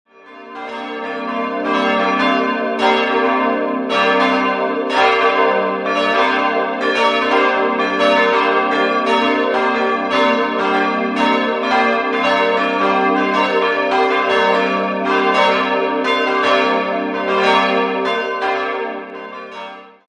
4-stimmiges ausgefülltes G-Moll-Geläute: g'-b'-c''-d'' Die Glocken wurden 1967 vom Bochumer Verein für Gussstahlfabrikation hergestellt. Auffällig im Geläute ist die b'-Glocke: Sie wurde in Dur-Rippe gegossen und hat einen sehr eigenwilligen, röhrenden Klang.
Amberg_Erloeserkirche.mp3